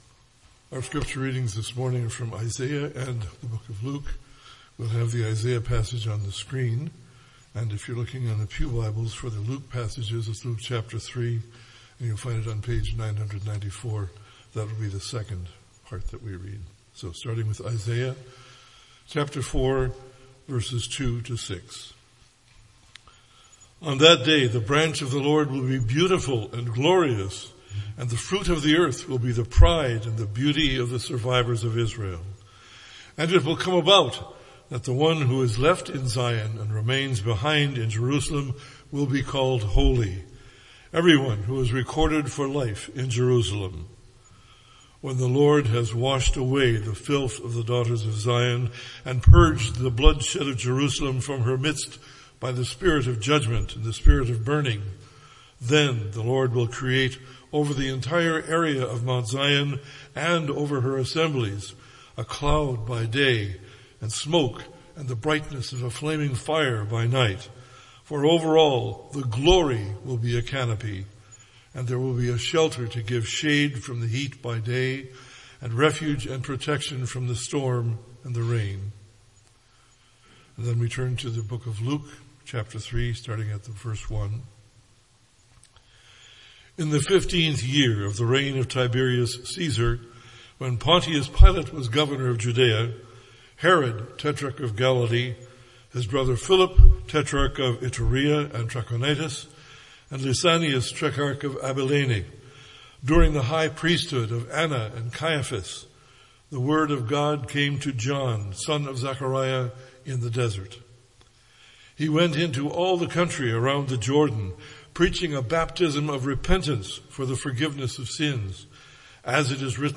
Luke: Gospel of Love – “Love New Baptised” :Bethesda Sermon Audio